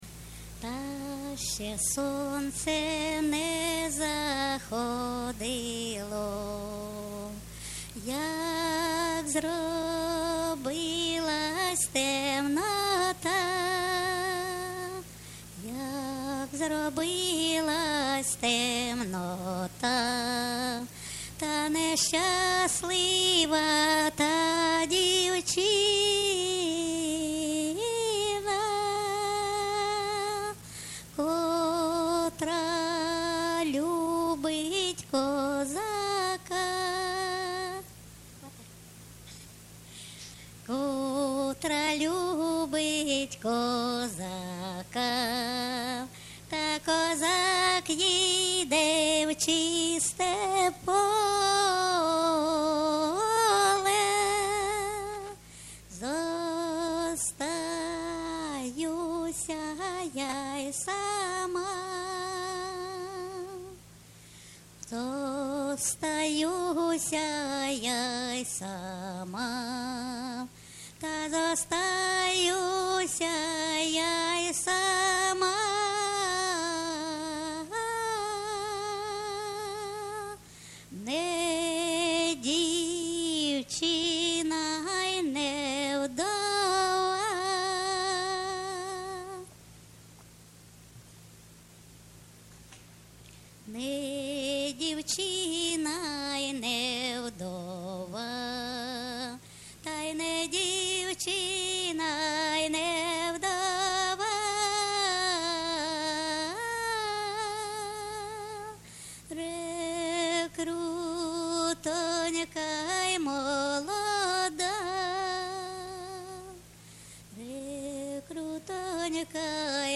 ЖанрПісні з особистого та родинного життя, Рекрутські
Місце записус-ще Новодонецьке, Краматорський район, Донецька обл., Україна, Слобожанщина